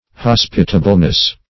Hospitableness \Hos"pi*ta*ble*ness\, n.
hospitableness.mp3